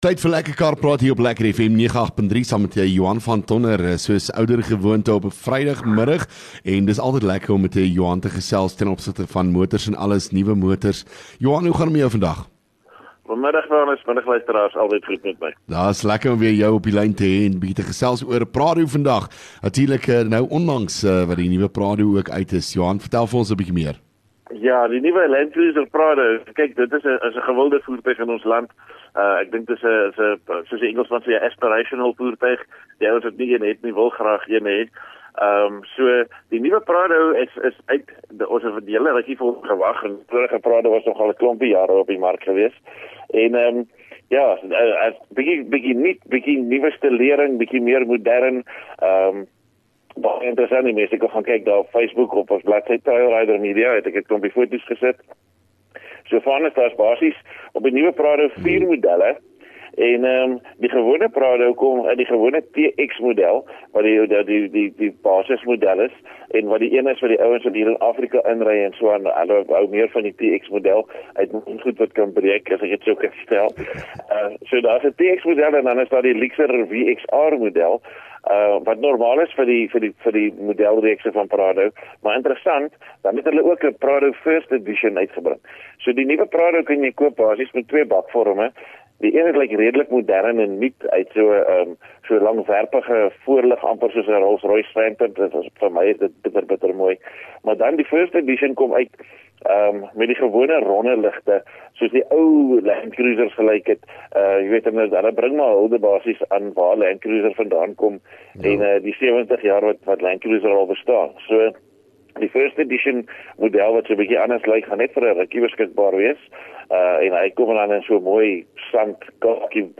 LEKKER FM | Onderhoude 7 Jun Lekker Kar Praat